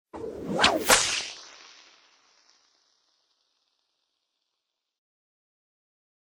Triffid Rumbles - Text Arrived Sound
A free MP3 download of the Triffid sting sound for Big Finish listeners - a recommended text alert!
triffids.-.sting..night.of.the.triffids..mp3